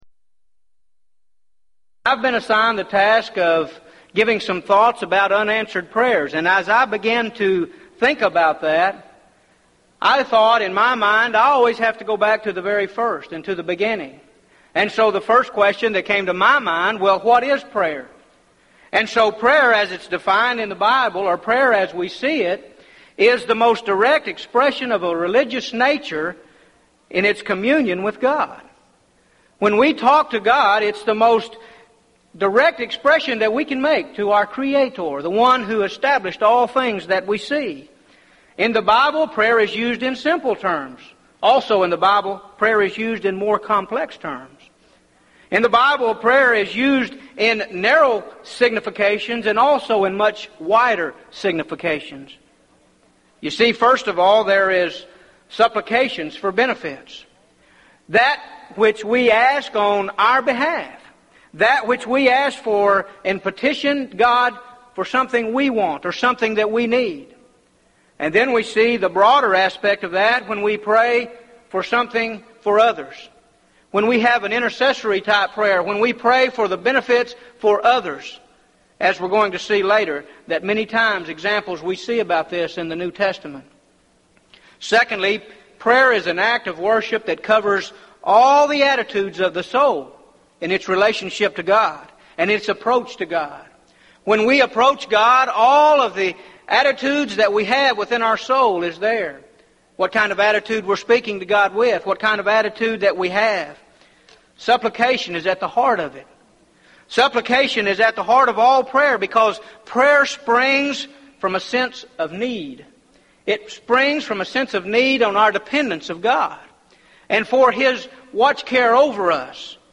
Series: Gulf Coast Lectures